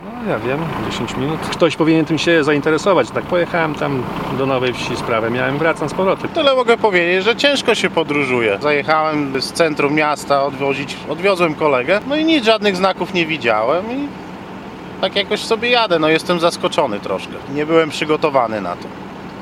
Kierowcy powiedzieli reporterowi Radia 5, ile już czekają w korku.